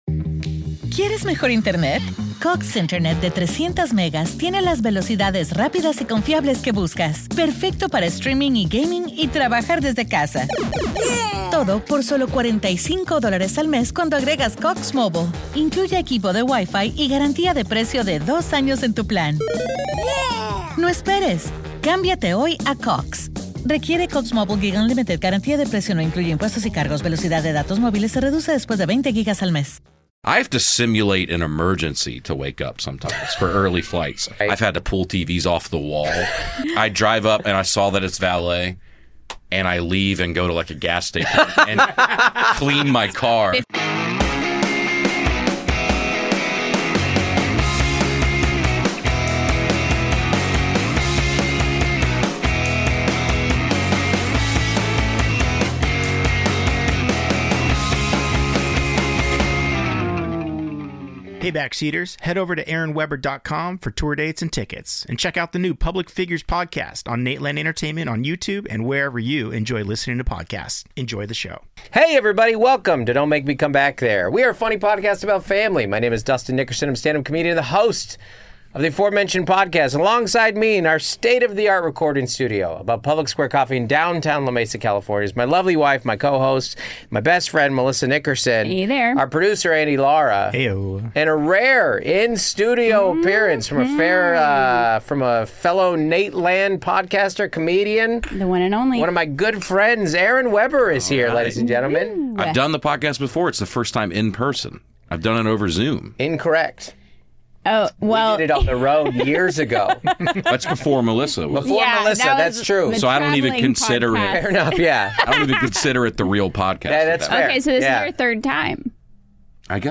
who joins us today on the show IN STUDIO!